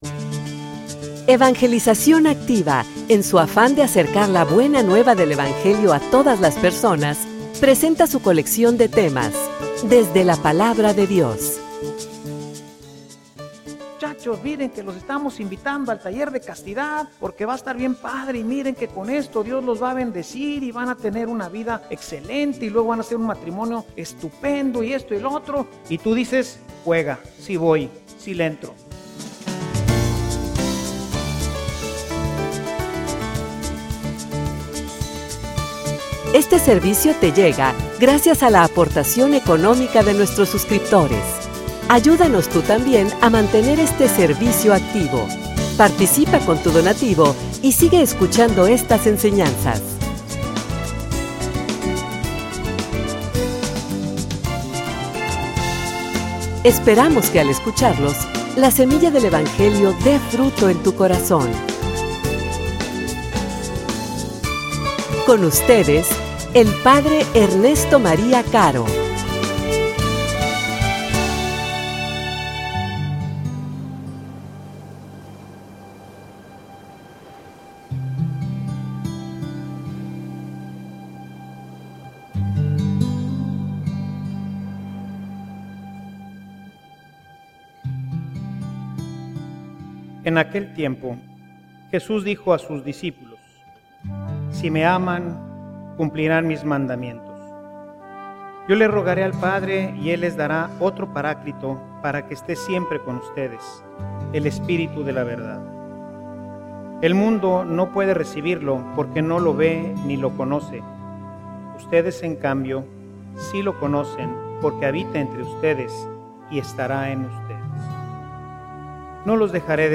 homilia_Honra_la_palabra_de_Dios_y_Dios_honrara_tu_obediencia.mp3